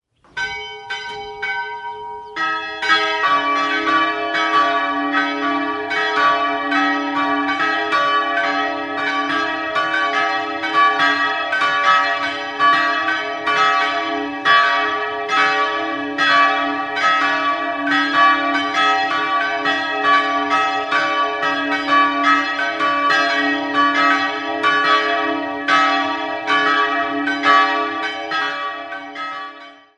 Martinsglocke d''-4 248 kg 726 mm 1957
Marienglocke e''-5 160 kg 642 mm 1957
Marienglocke g''-5 110 kg 540 mm 1706